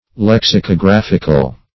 \Lex`i*co*graph"ic*al\ (l[e^]ks`[i^]*k[-o]*gr[a^]f"[i^]*kal), a.